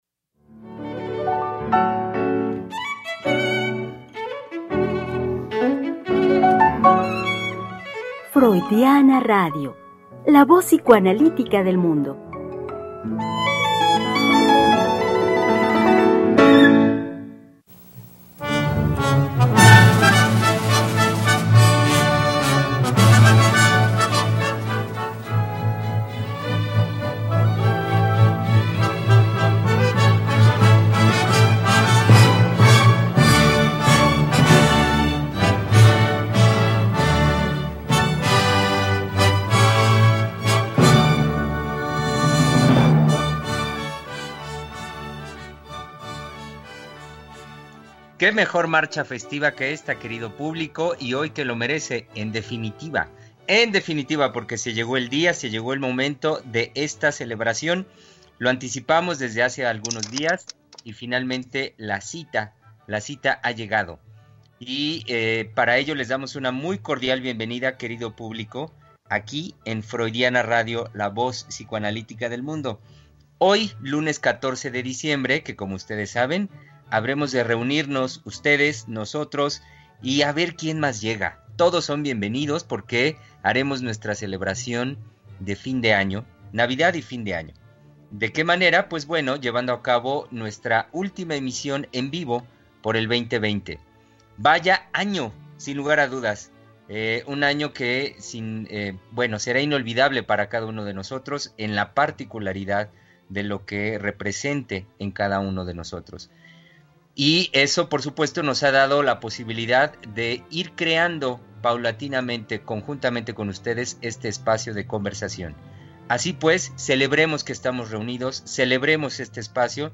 Cerramos las actividades del año con un gran especial navideño con todos los psicoanalistas de Freudiana Radio.
Programa transmitido el 14 de diciembre del 2020.